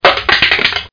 1 channel
CRUSH3.mp3